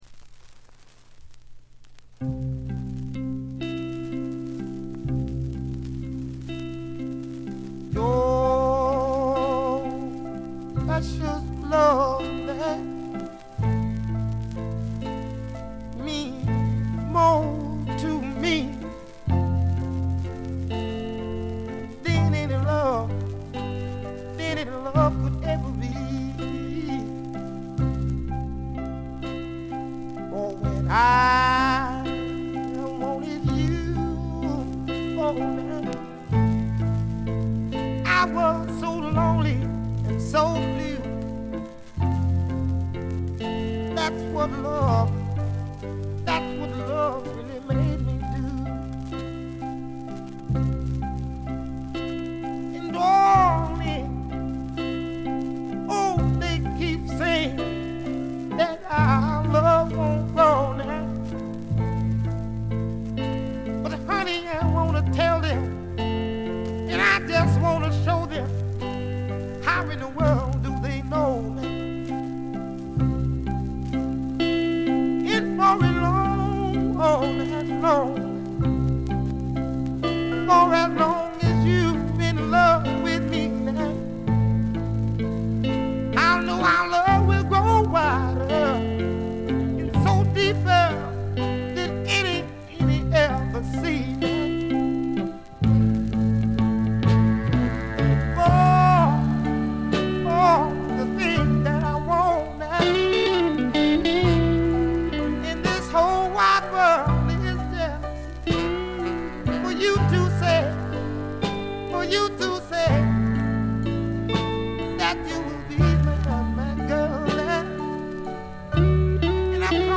静音部でのバックグラウンドノイズ程度。鑑賞を妨げるようなノイズはありません。
試聴曲は現品からの取り込み音源です。